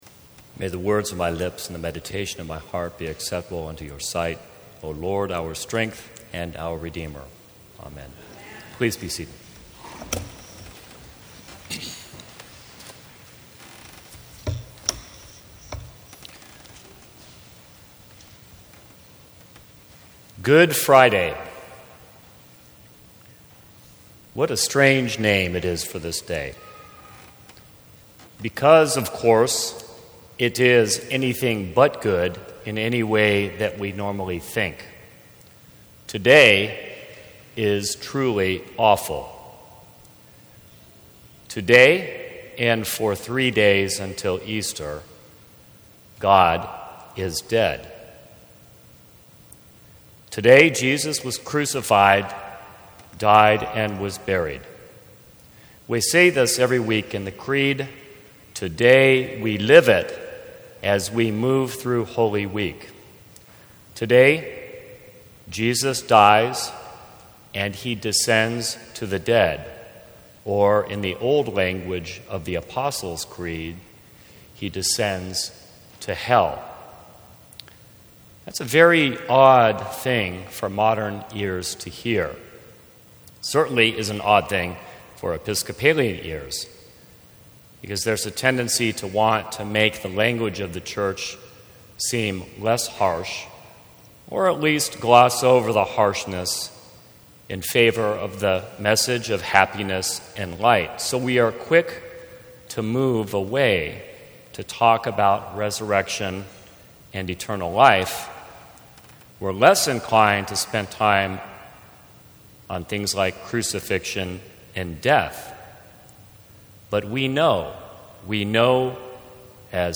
Sermons from St. Cross Episcopal Church Good Friday Mar 25 2016 | 00:15:29 1x 00:00 / 00:15:29 Subscribe Share Apple Podcasts Spotify Overcast RSS Feed Share Link Embed